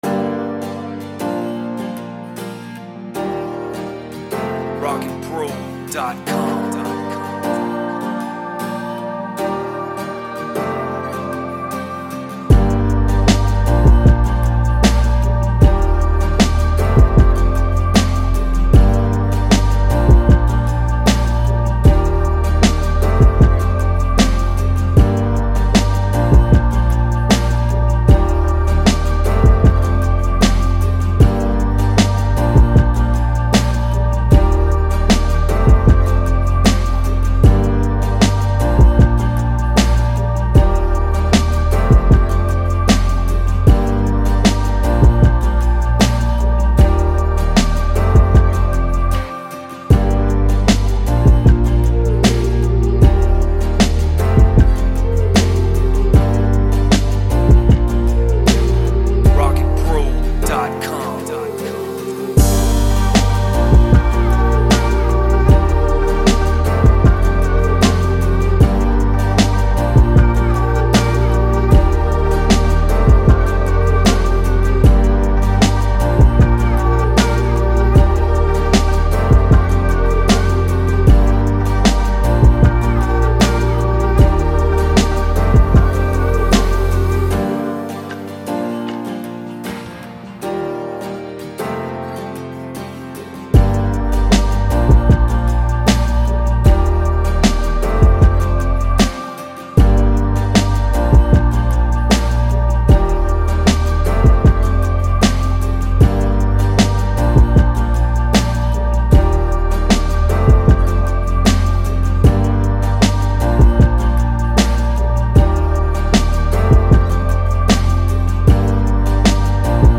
Sad, emotional rap beat with pianos, strings, and choirs.